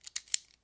工具 剃刀片 In02
描述：正在处理盒式切割机的声音。 此文件已标准化，大部分背景噪音已删除。没有进行任何其他处理。
标签： 切割机 缩回 刀片 刀具延伸 剃须刀
声道立体声